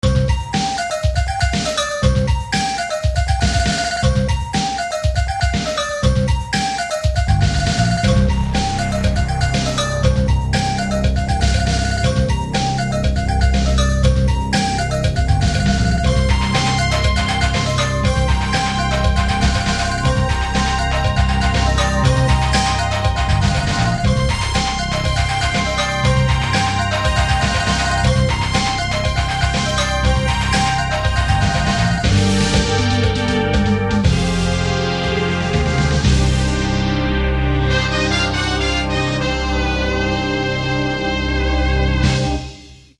Jazzy synth piece with rap break in the middle.